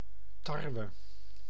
Ääntäminen
Vaihtoehtoiset kirjoitusmuodot (vanhentunut) hvete Ääntäminen Tuntematon aksentti: IPA: /ˈveː.tɛ/ Haettu sana löytyi näillä lähdekielillä: ruotsi Käännös Ääninäyte 1. tarwe {m} Artikkeli: ett .